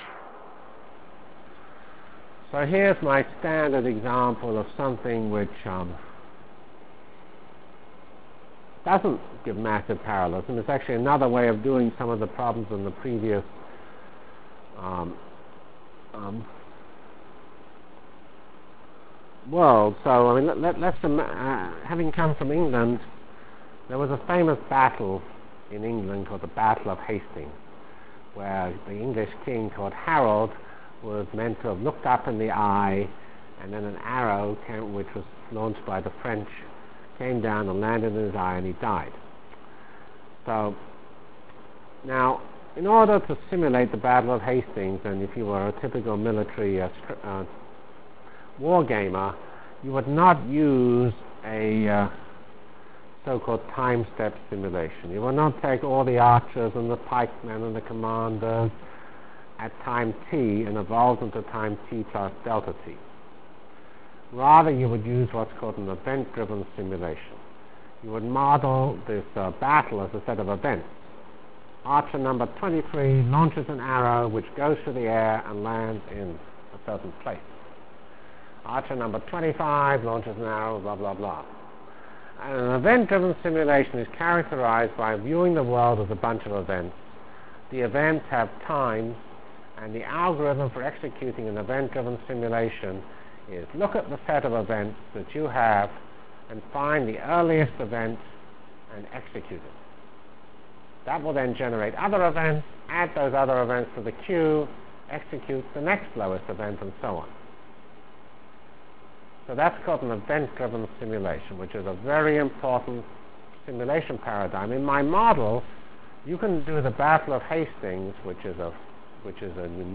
From CPS615-Introduction to F90 Features, Rationale for HPF and Problem Architecture Delivered Lectures of CPS615 Basic Simulation Track for Computational Science -- 24 September 96. by Geoffrey C. Fox